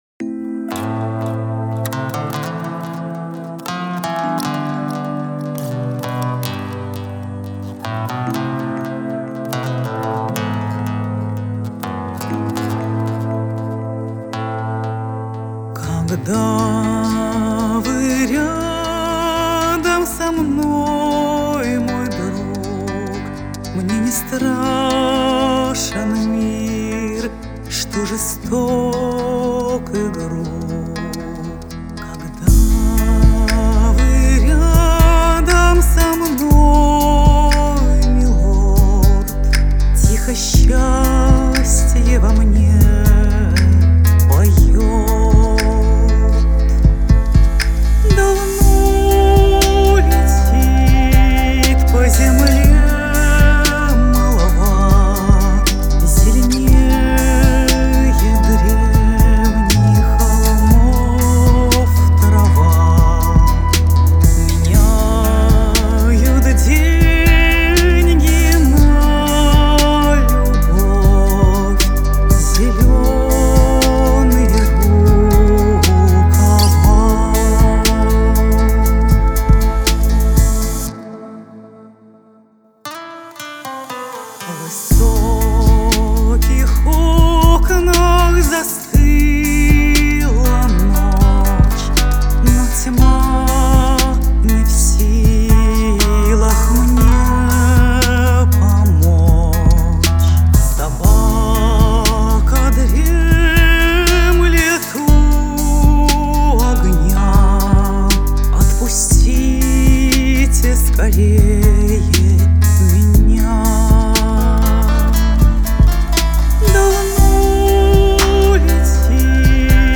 Благодаря этому сотрудничеству в моем репертуаре появилась песня «Зелёные рукава», это старинная английская песня, я ее с детства играла по нотам на гитаре, а сейчас записала как кавер в авторской аранжировке и исполнила на модном шоу с собачками в старинном замке.